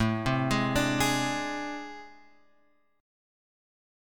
AmM11 chord {5 3 6 x 3 4} chord